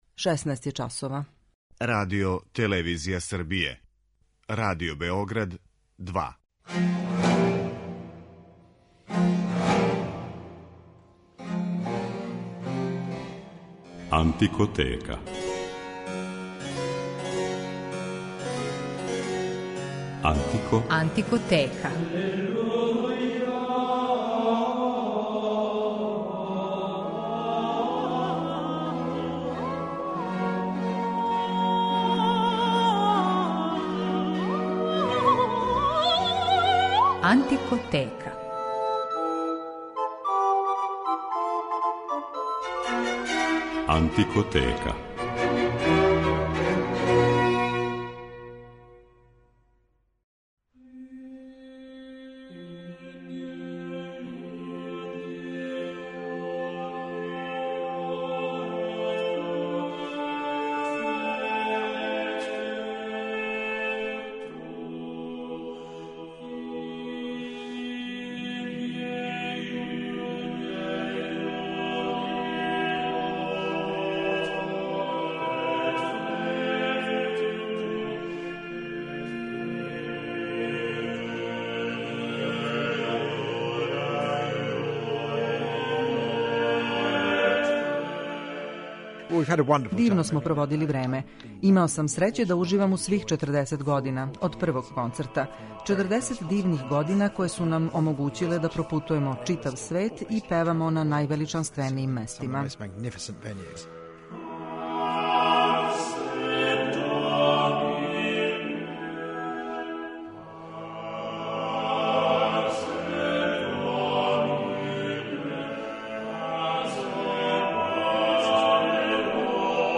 Данашња емисија је посвећена једном од најбољих вокалних ансамбала на свету - британском мушком квартету Хилијард, који је 2014, у години када је прослављао 40. рођендан, одлучио да се заувек опрости од публике.
Ове врхунске музичаре, који су током читаве каријере равноправно изводили и рану и савремену музику, у Антикотеци ћемо ипак представити у литератури са којом су се прославили - у музици средњег века и ренесансе.